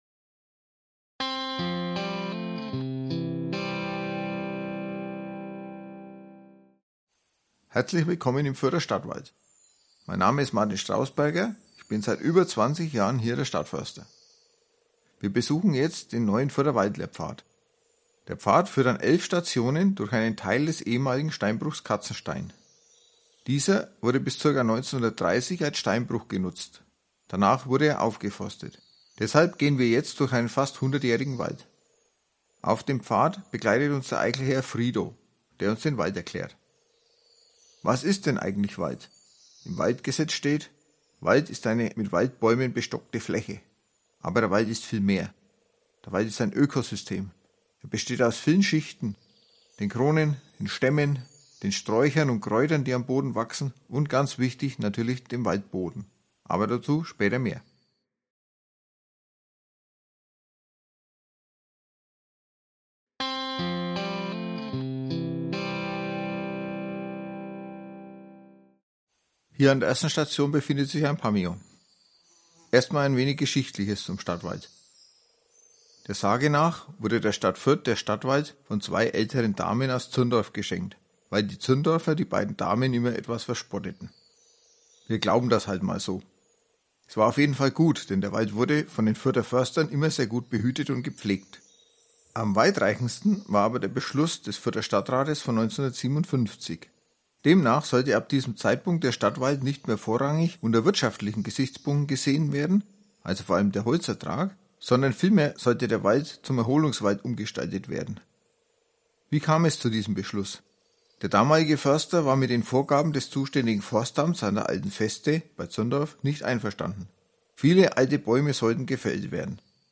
Audioführung über den Waldlehrpfad
Audioguide_Waldlehrpfad_Fuerther_Stadtwald_komp.mp3